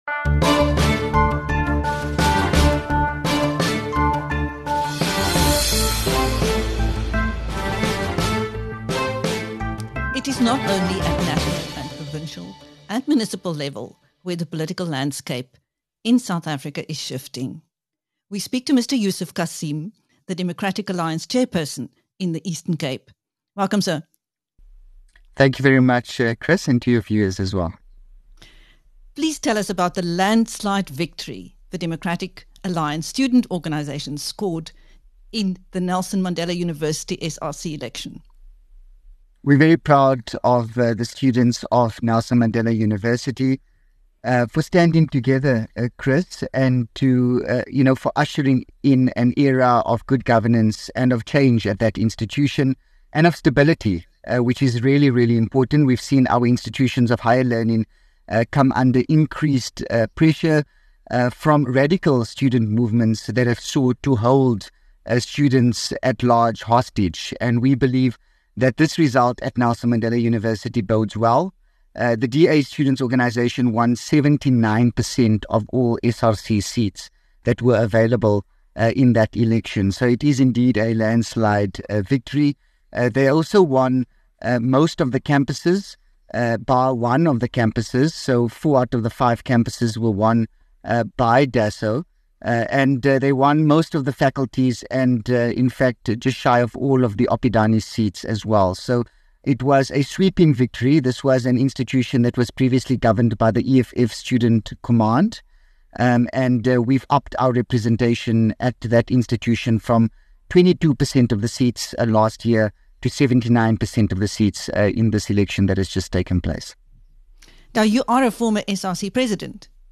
In a dramatic shift in campus politics, the Economic Freedom Fighters (EFF) Student Command has lost control of Nelson Mandela University SRC to the Democratic Alliance Students Organisation (DOSA). In this interview with BizNews, Yusuf Cassim, the Democratic Alliance chairperson in the Eastern Cape, says: “We've seen our institutions of higher learning come under increased pressure from radical student movements that have sought to hold students at large hostage.